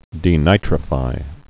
(dē-nītrə-fī)